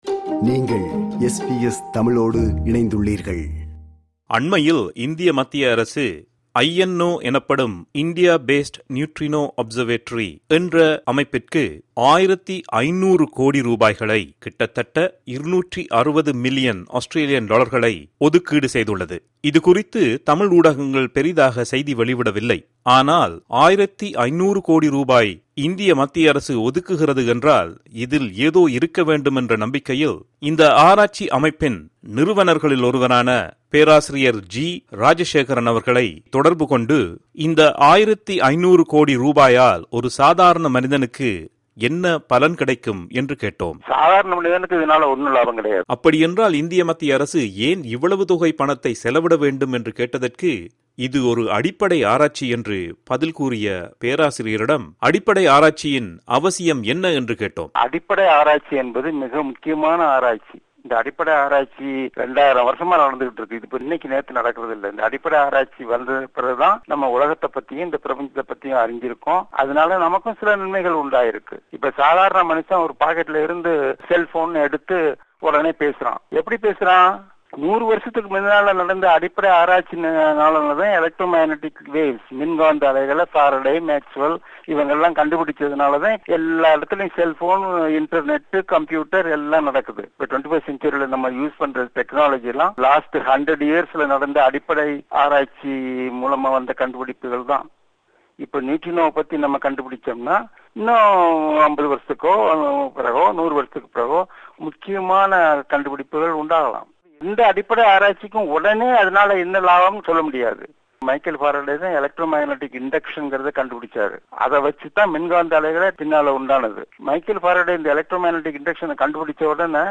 This interview was first broadcast in September 2013.